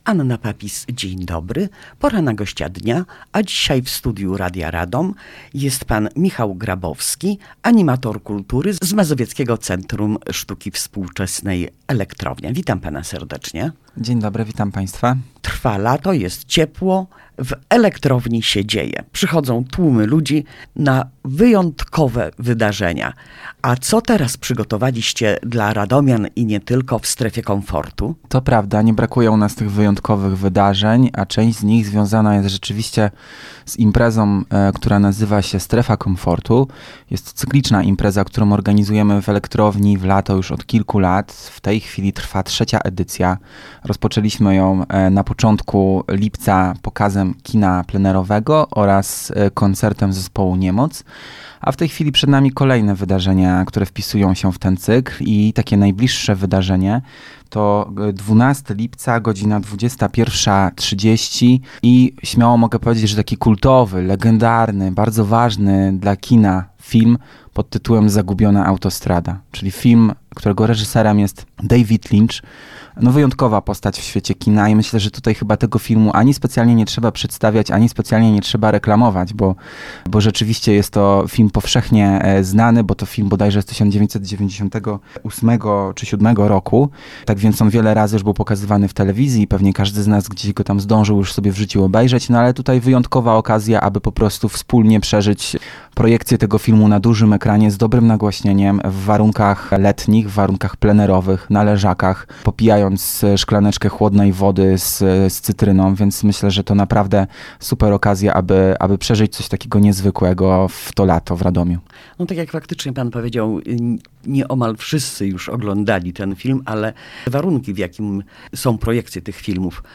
w studiu Radia Radom